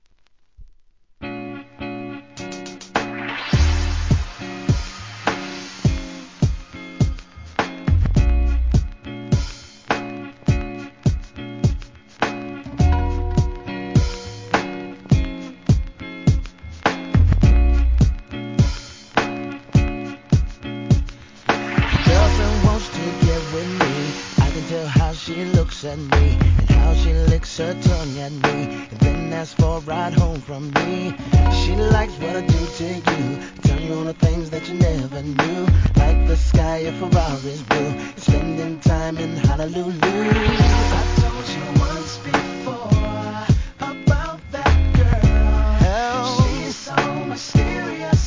HIP HOP/R&B
ソウルフルなVOCALで聴かせます!!